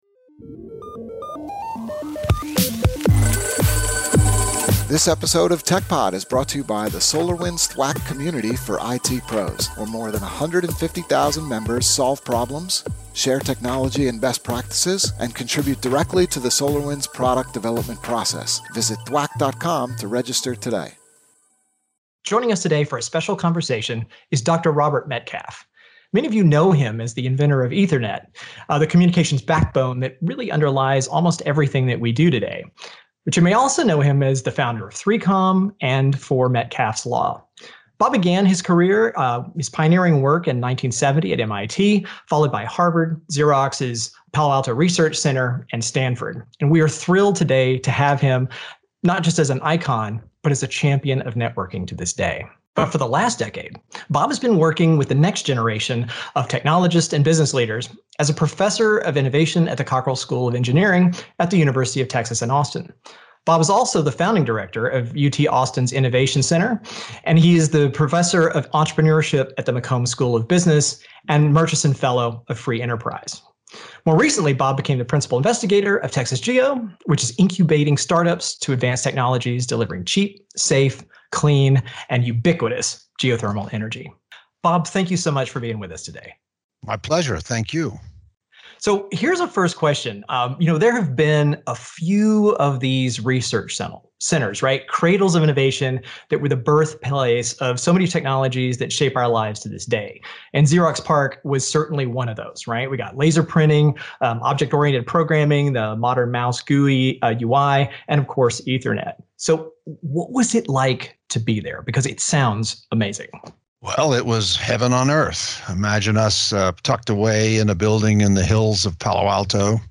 A Conversation with Robert Metcalfe (Part 1)